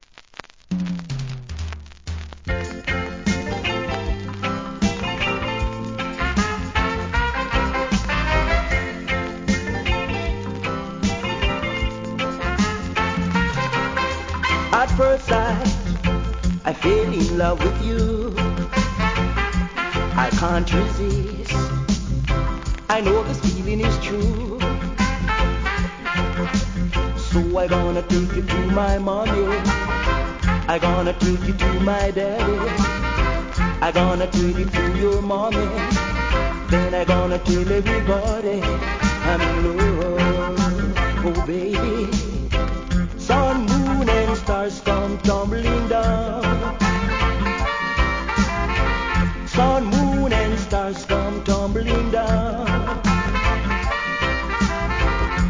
REGGAE
ほのぼのマイナー物!